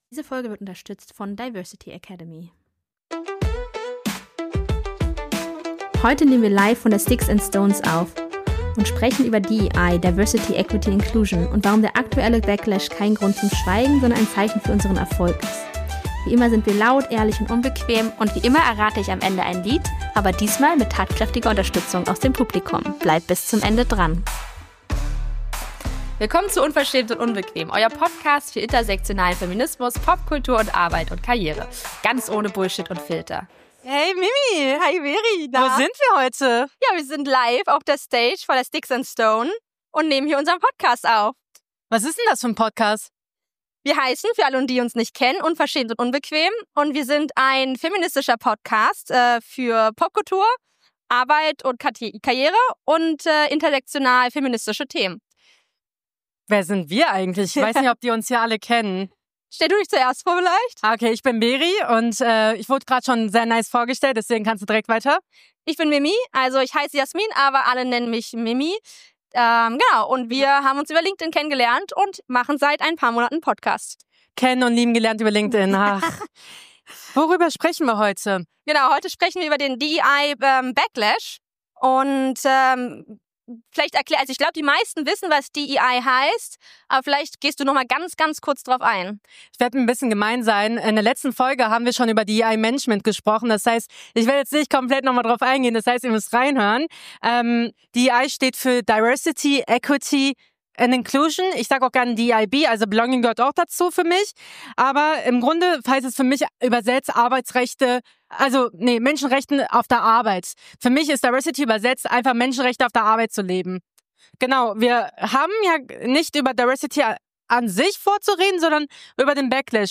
Live aufgenommen auf der Sticks & Stones 2025 Thema: DEI-Backlash – Wenn Vielfalt unbequem wird Worum es geht Was bedeutet eigentlich DEI (Diversity, Equity & Inclusion) – und warum sprechen wir lieber von Menschenrechten am Arbeitsplatz?